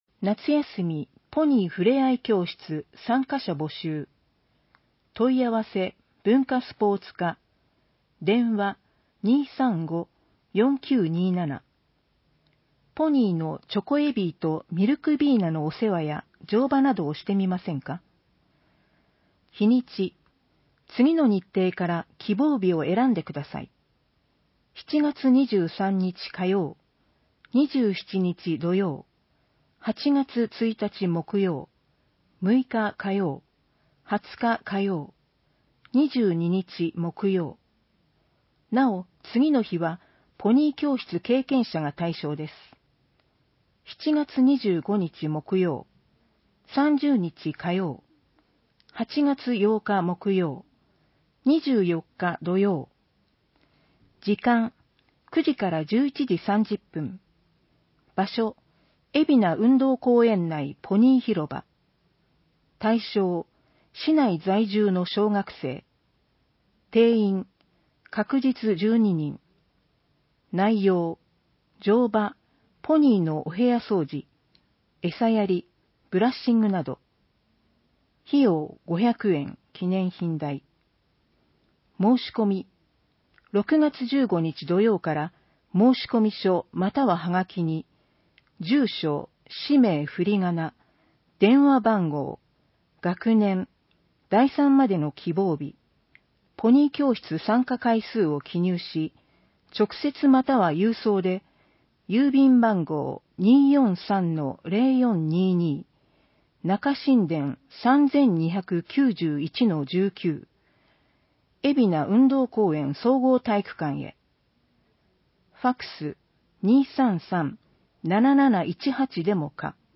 広報えびな 令和元年6月15日号（電子ブック） （外部リンク） PDF・音声版 ※音声版は、音声訳ボランティア「矢ぐるまの会」の協力により、同会が視覚障がい者の方のために作成したものを登載しています。